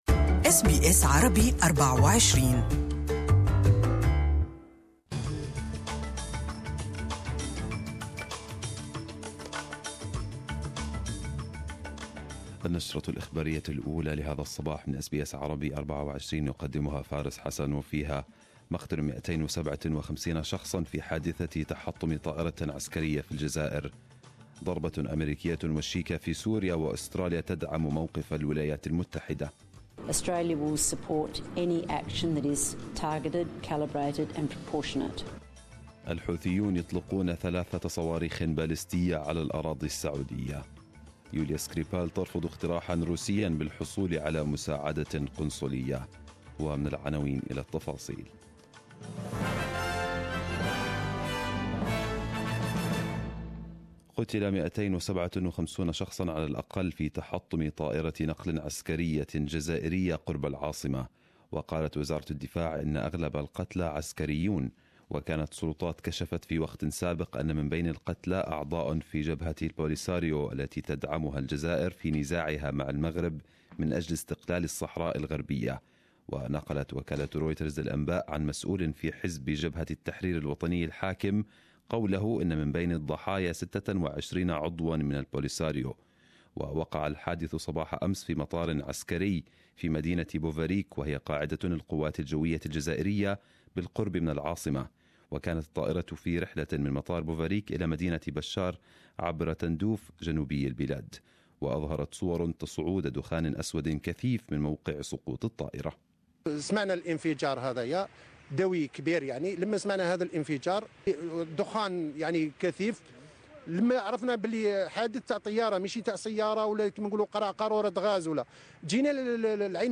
Arabic News Bulletin 12/04/2018